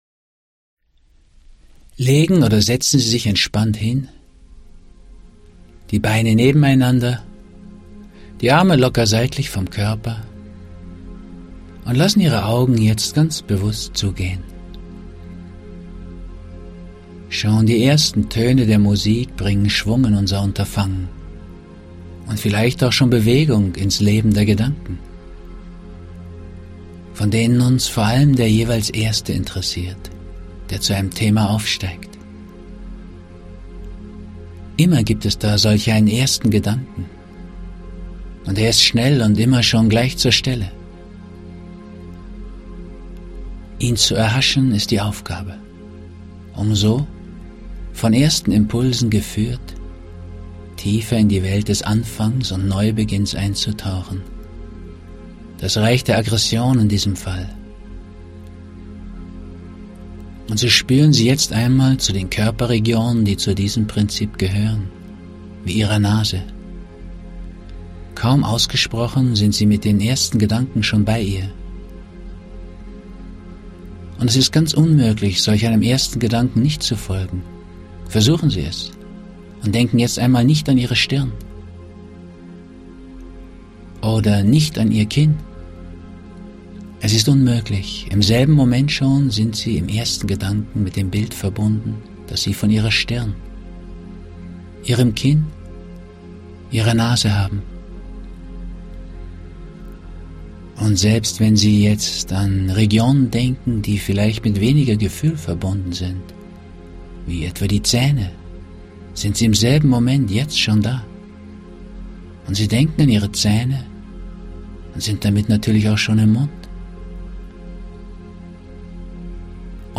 Übungen und Meditationen zu Selbsterkenntnis, Vorbeugung und Heilung